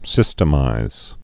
(sĭstə-mīz)